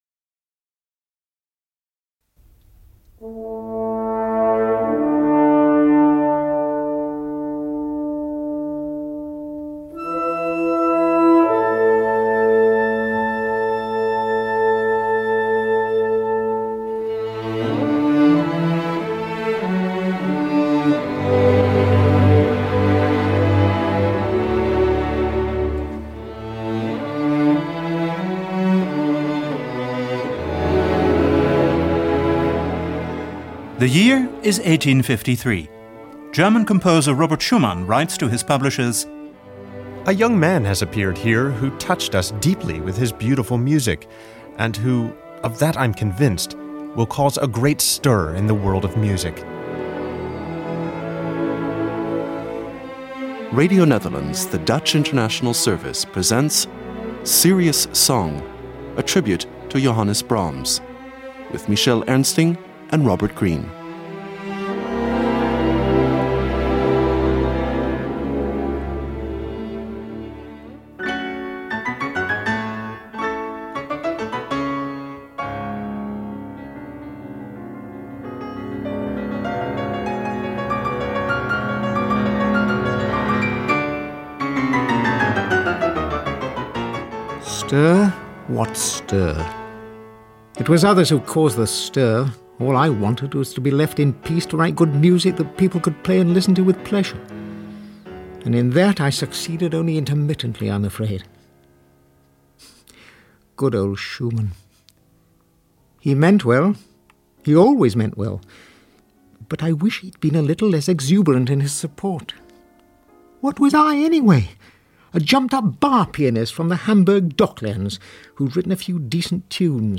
With music, anecdotes and readings.